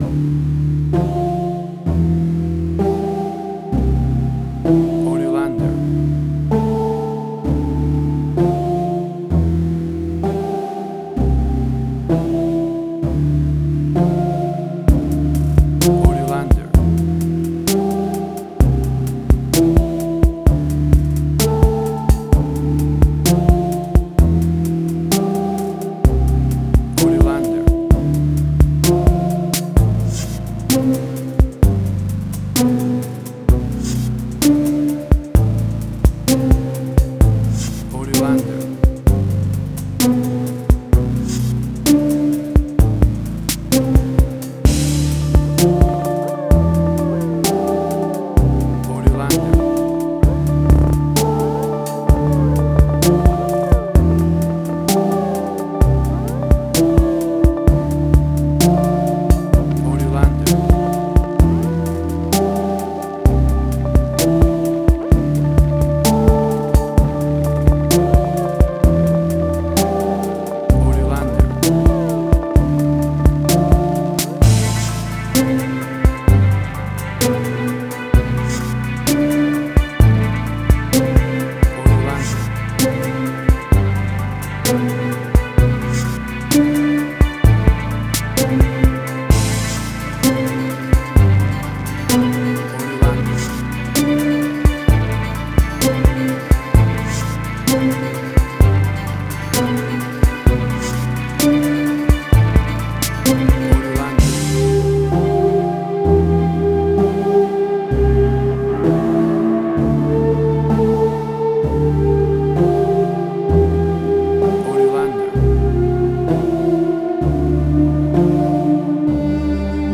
Steampunk Sci-fi.
Tempo (BPM): 64